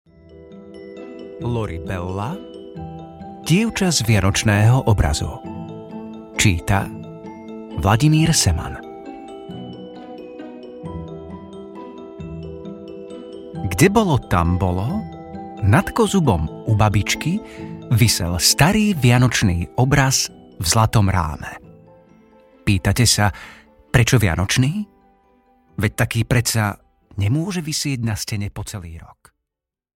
Dievča z vianočného obrazu audiokniha
Ukázka z knihy